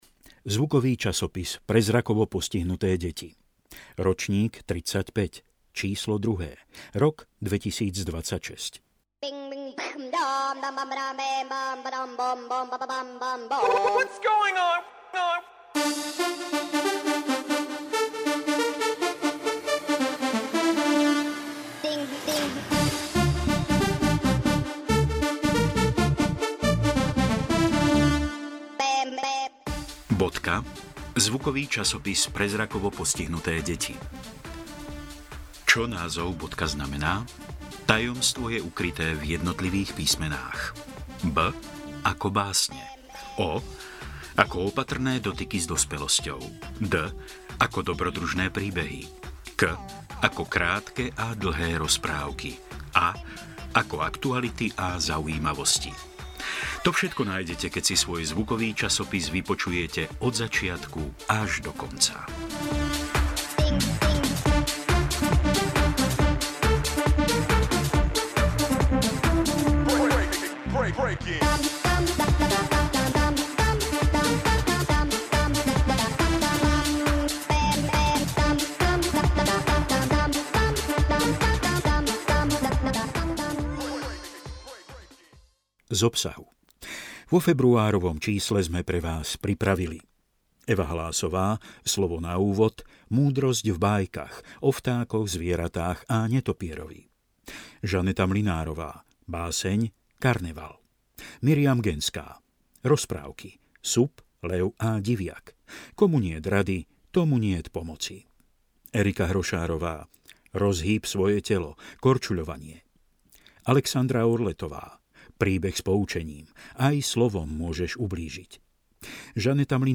Časopis BODKA Vytlačiť E-mail Bodka – zvukový časopis pre zrakovo postihnuté deti Čo názov Bodka znamená?
Vyrába Zvukové štúdio Slovenskej knižnice pre nevidiacich Mateja Hrebendu v Levoči.